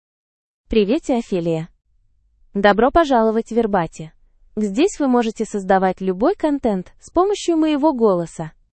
Ophelia — Female Russian AI voice
Ophelia is a female AI voice for Russian (Russia).
Voice: OpheliaGender: FemaleLanguage: Russian (Russia)ID: ophelia-ru-ru
Voice sample
Listen to Ophelia's female Russian voice.